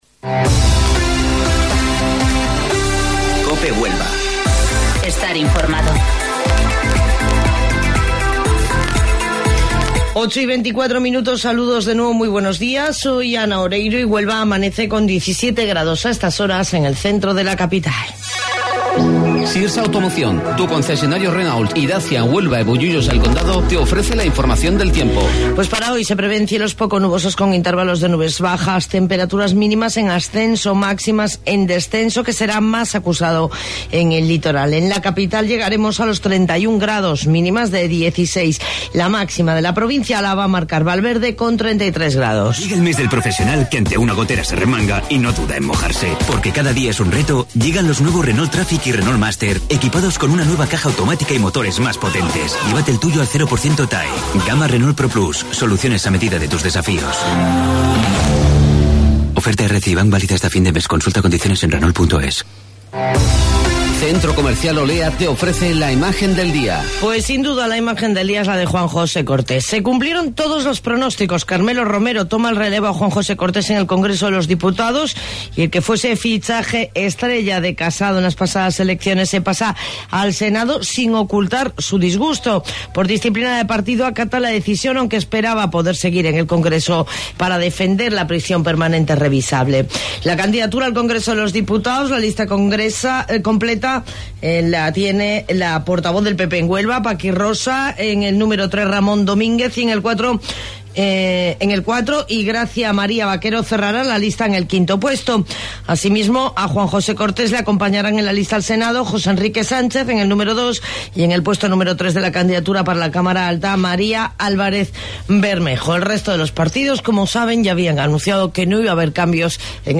AUDIO: Informativo Local 08:25 del 8 de Octubre